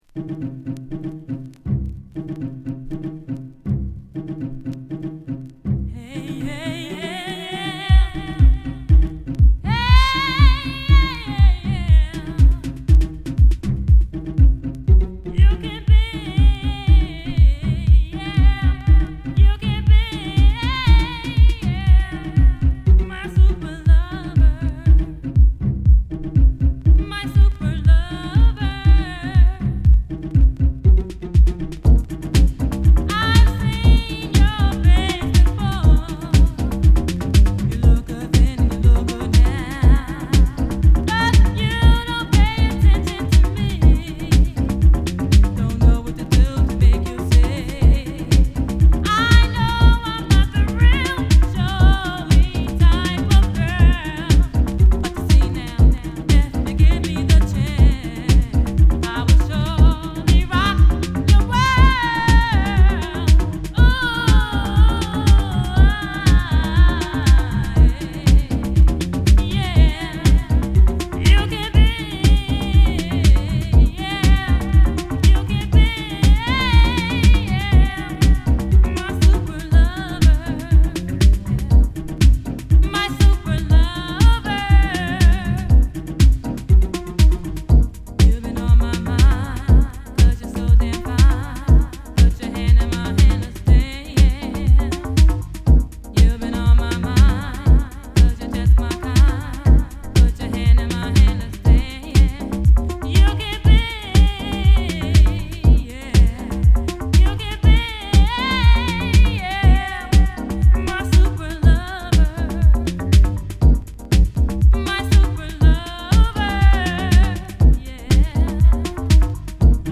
c/wは、針滑り音を効果的に使ったハウス・クラシックを収録！！